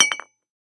Dropped Object